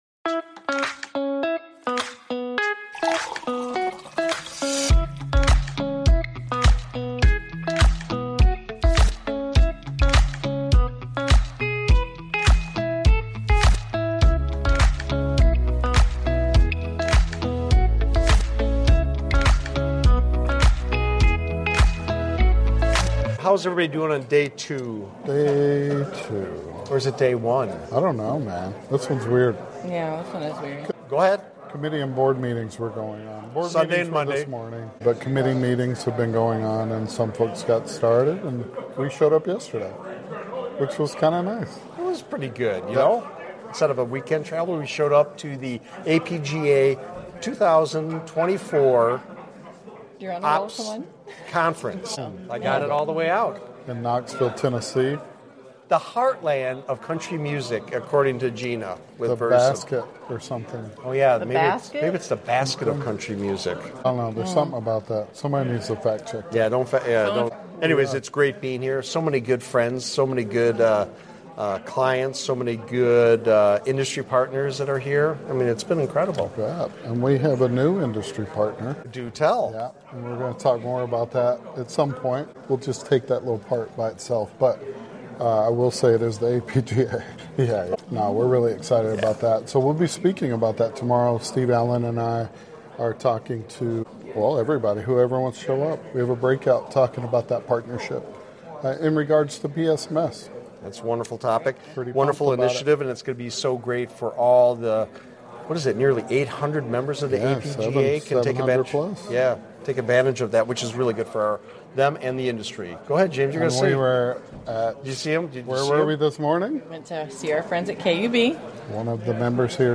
Live @ American Public Gas Association Fall Operations Conference